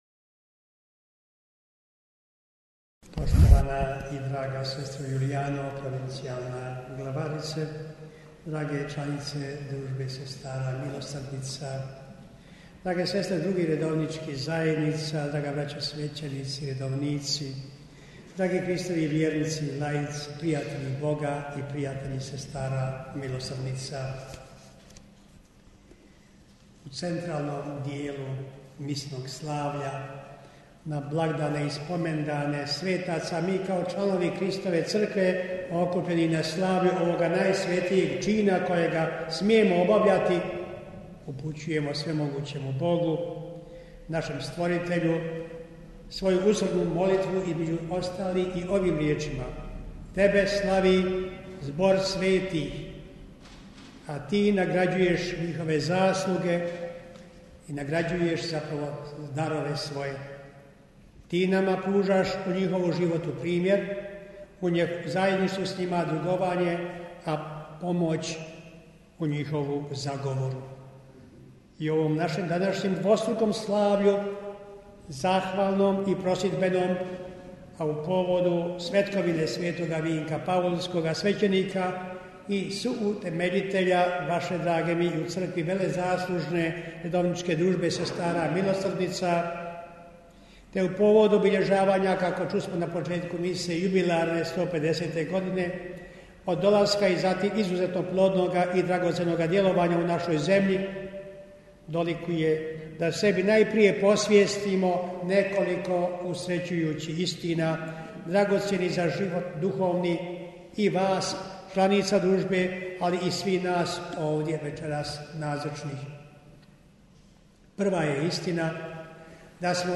AUDIO: PROPOVIJED BISKUPA KOMARICE TIJEKOM MISE NA PATRON CRKVE SV. VINKA U SARAJEVU - BANJOLUČKA BISKUPIJA
Svečano Euharistijsko slavlje na patron samostanske crkve Družbe sestara Milosrdnica posvećene njihovu utemeljitelju sv. Vinku Paulskom u središtu Sarajeva, u ponedjeljak, 27. rujna 2021. u večernjima predvodio je biskup banjolučki mons. Franjo Komarica uz koncelebraciju devetorice svećenika.